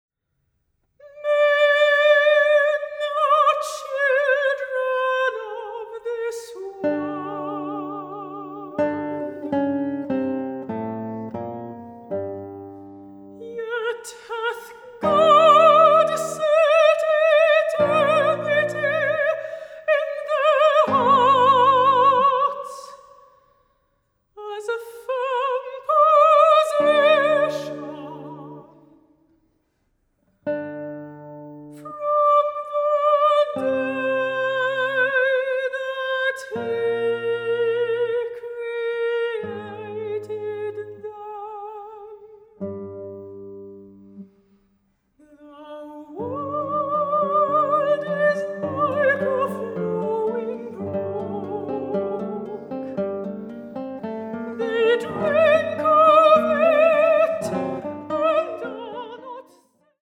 Gitarre
Gesang